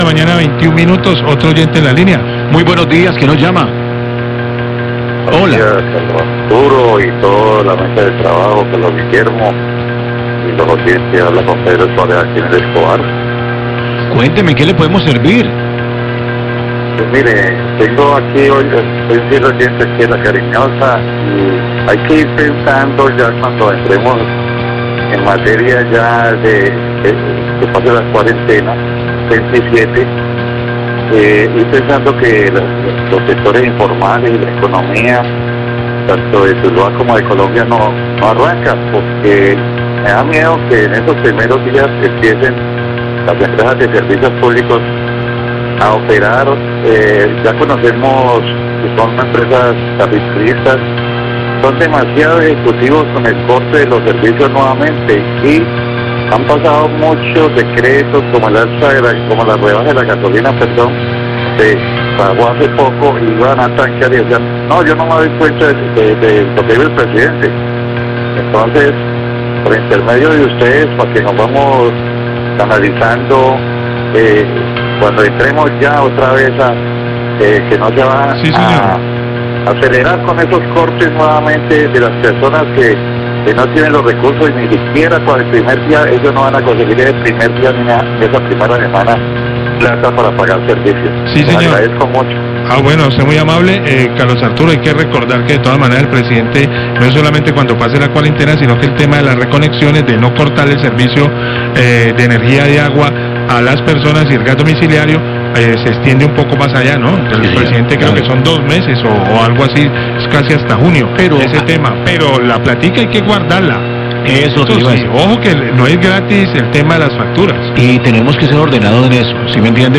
Radio
Llamada de oyente que solicita información sobre la manera en la que se podrán pagar los recibos de servicios públicos durante y después de la cuarenta, así mismo expresa su preocupación por la posibilidad de que lleguen los cortes.